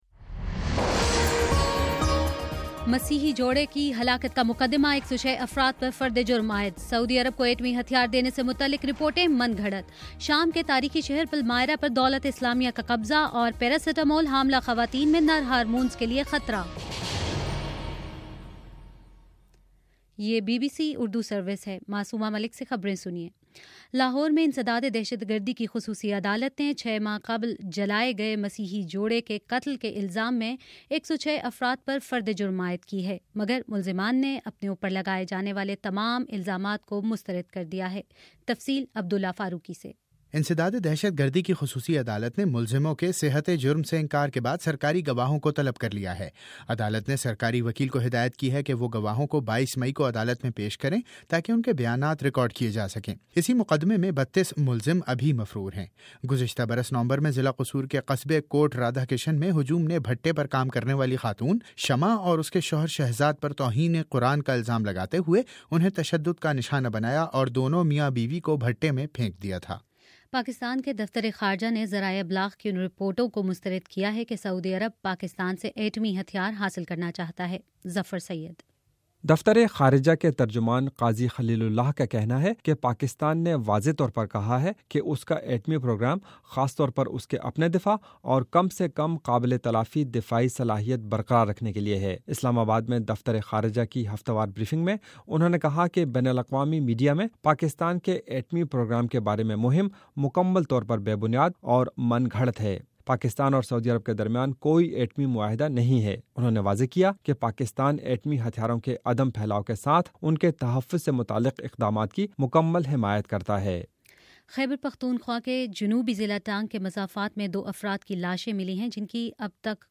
مئی 21: شام چھ بجے کا نیوز بُلیٹن